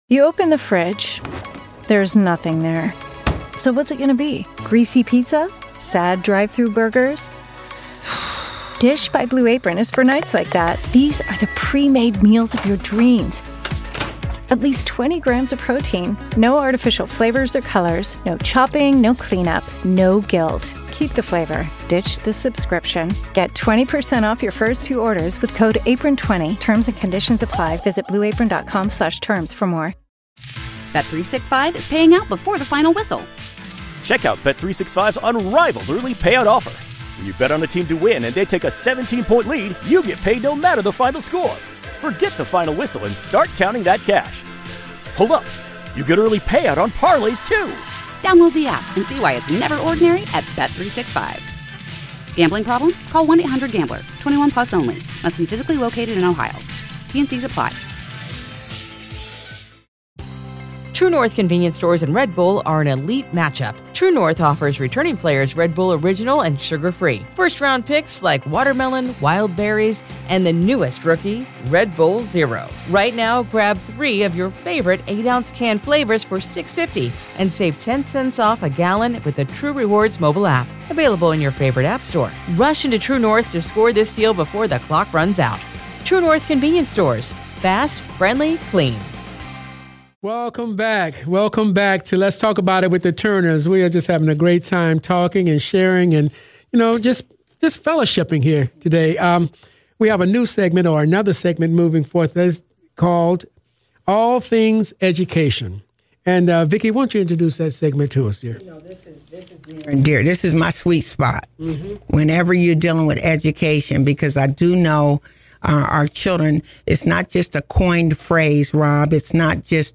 Each panelist brought valuable insights based on their extensive experience in educational environments.